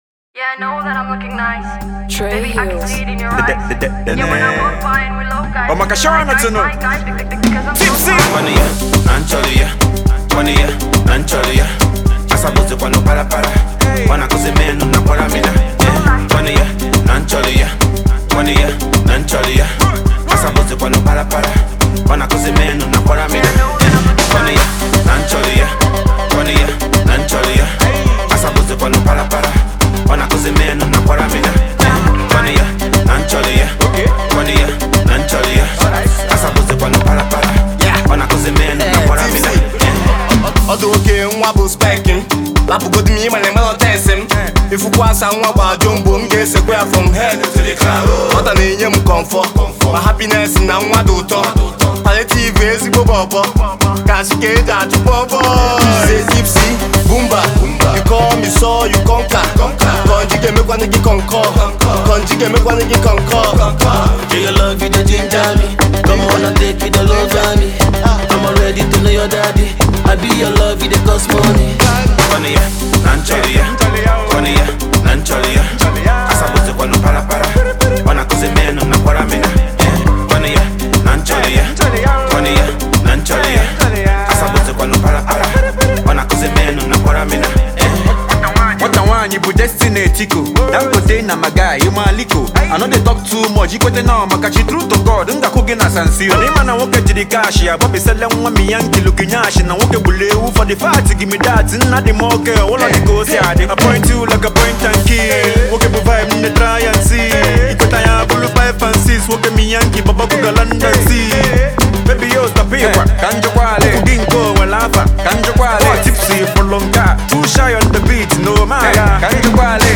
Amapiano-infused song